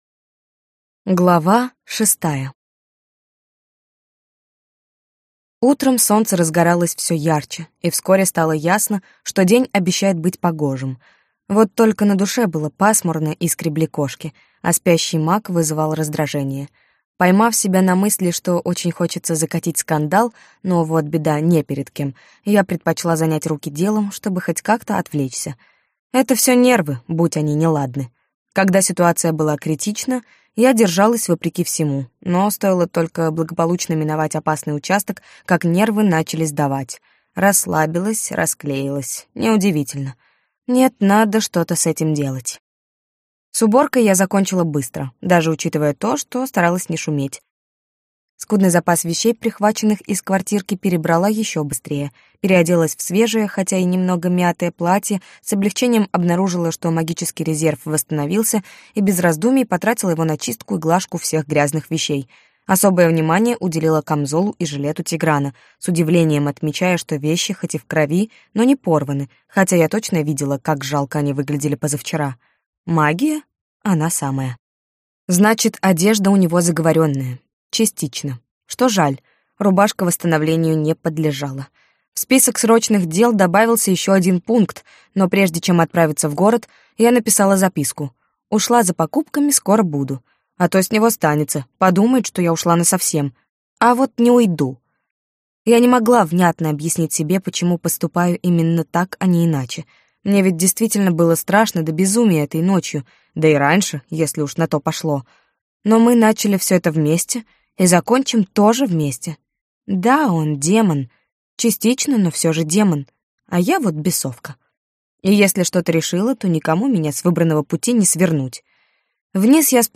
Аудиокнига Монстр из-под кровати - купить, скачать и слушать онлайн | КнигоПоиск